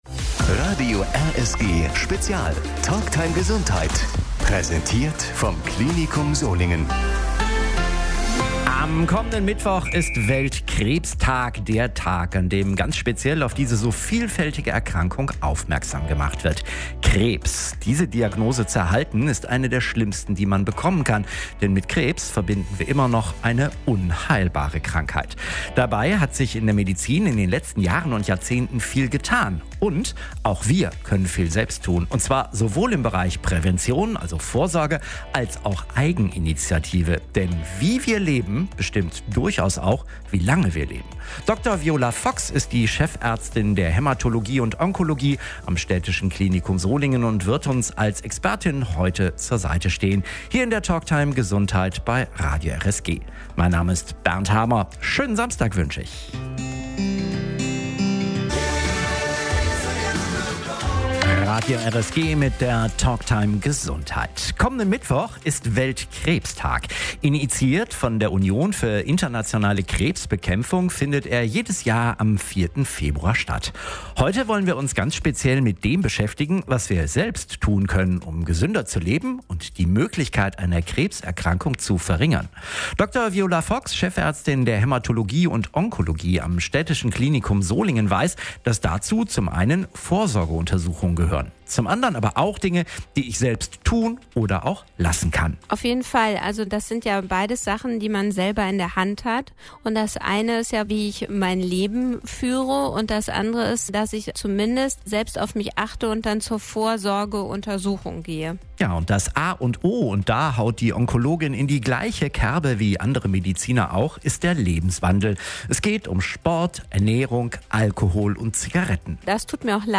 Die Talktime Gesundheit läuft monatlich bei Radio RSG und wird präsentiert vom Klinikum Solingen